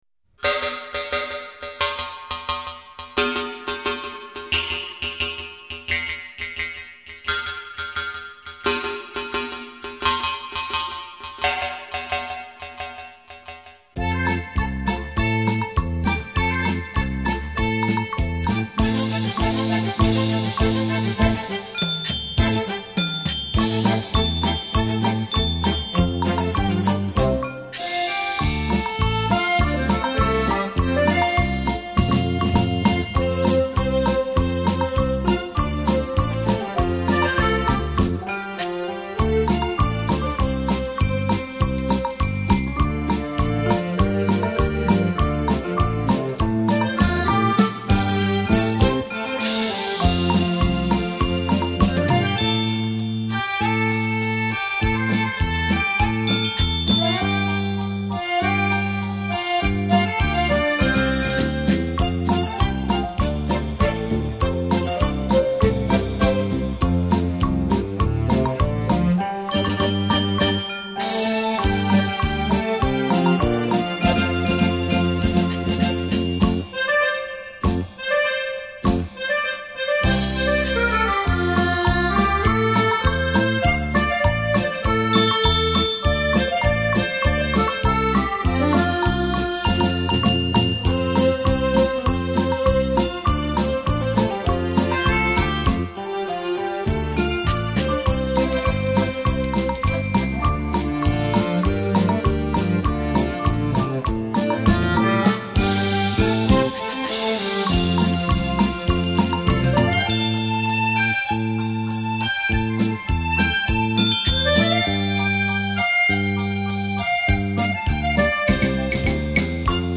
閒適優雅的音符，完美傳真的音質，呈獻在您的耳際
優美動聽的音樂旋律讓人沉醉其中...